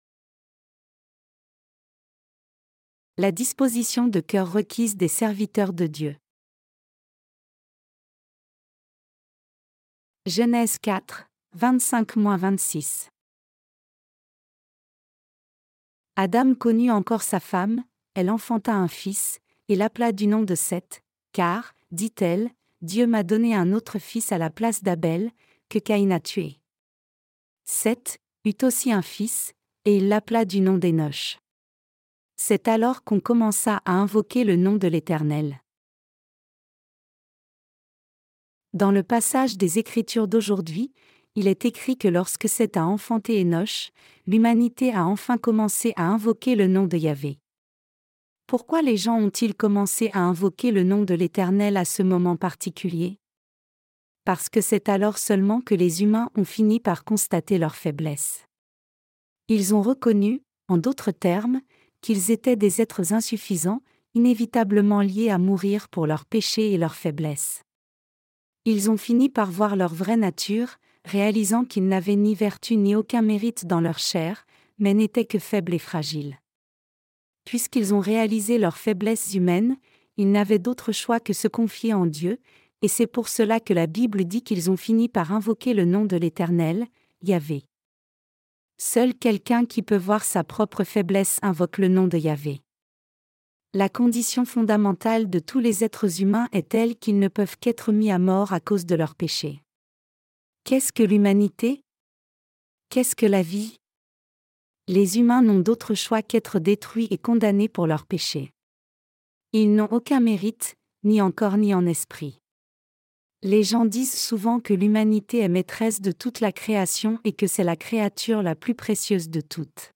Sermons sur la Genèse (V) - LA DIFFERENCE ENTRE LA FOI D’ABEL ET LA FOI DE CAÏN 7.